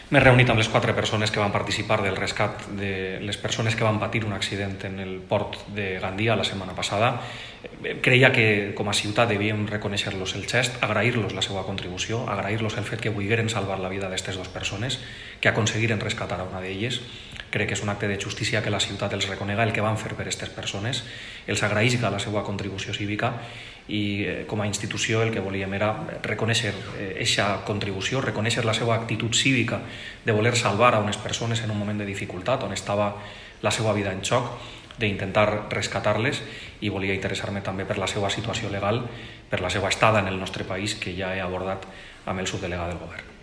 alcalde de Gandia (audio).